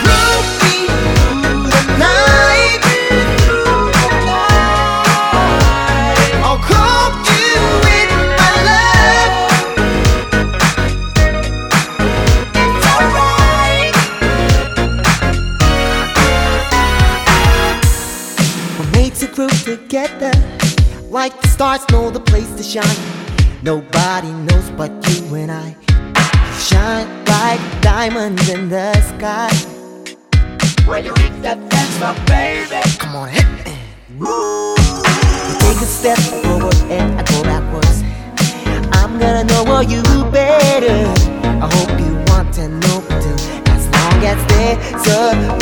ソウルフルで伸びやかなヴォーカルはもちろん曲、バックの演奏も日本人離れした驚愕のセンスと実力！